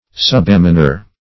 Subalmoner \Sub*al"mon*er\